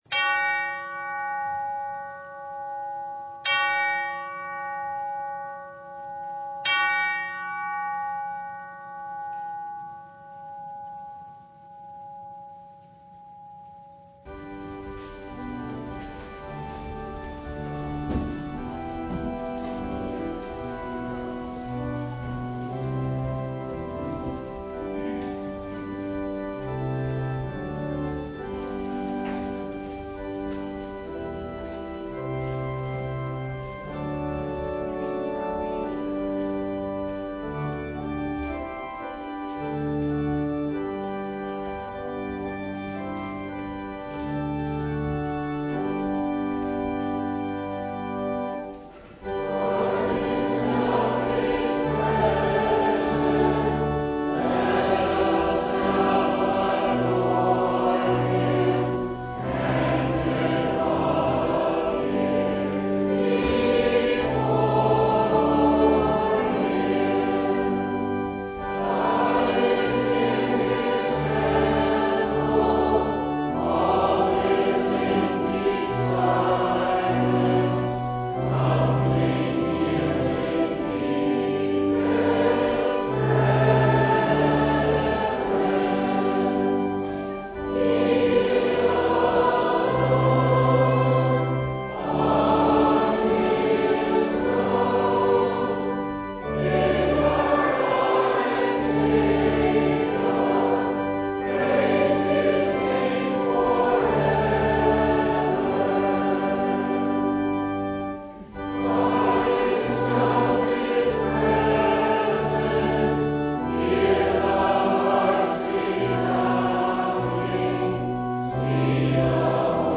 Passage: Luke 9:51-62 Service Type: Service of the Word
Full Service